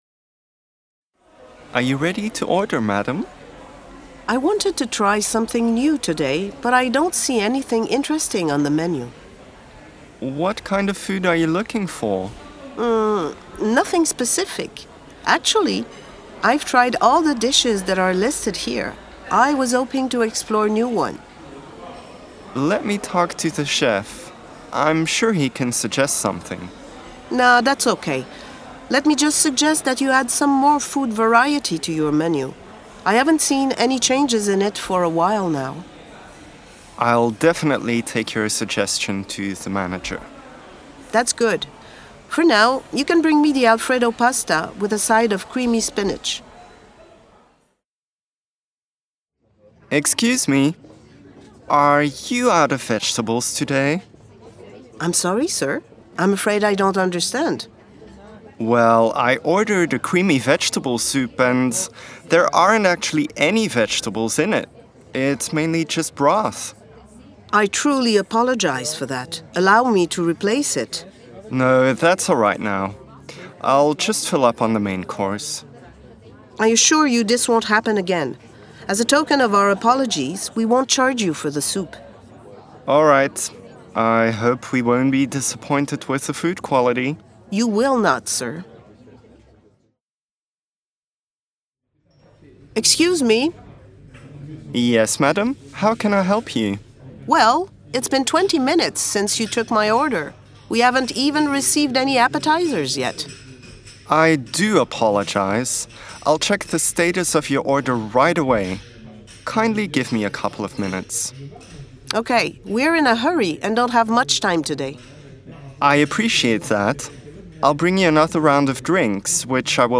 Now, listen to the three dialogues and answer the questions below.